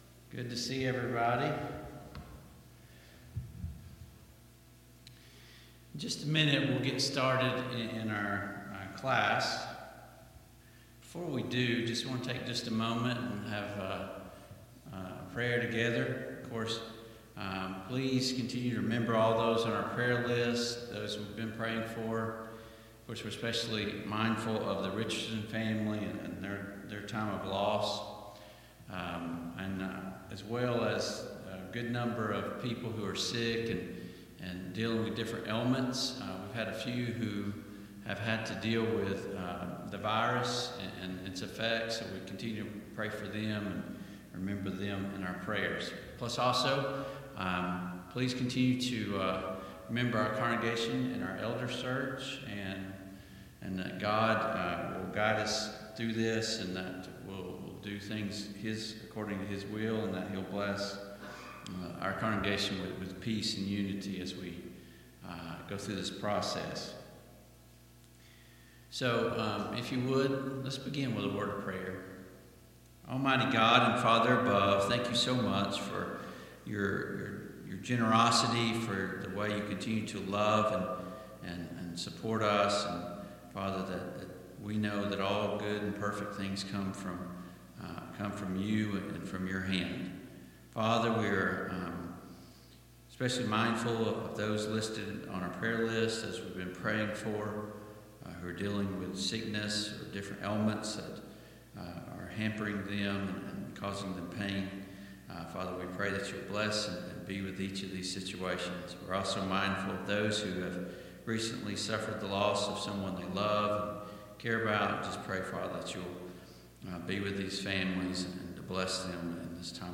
Service Type: Sunday Morning Bible Class Topics: Addiction , Influence , Social Drinking